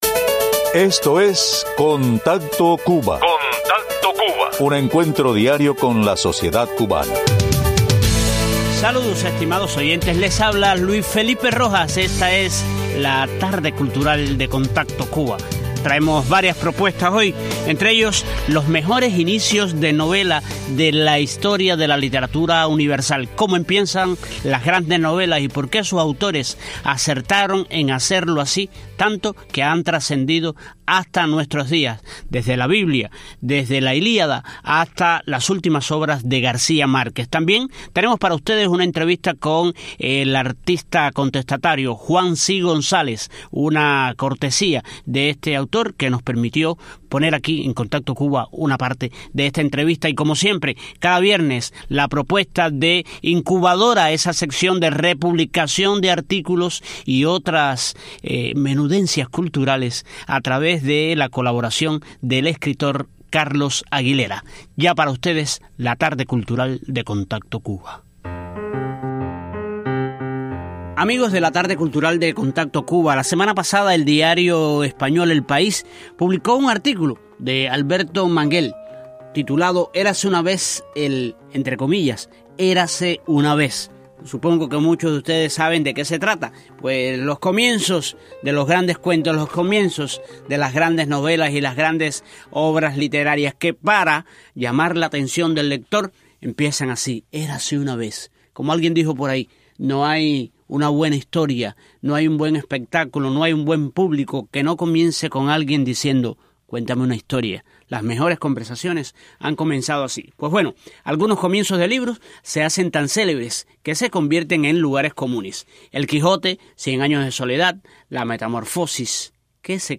Concurso, entrevista de arte en la tarde cultural de Contacto Cuba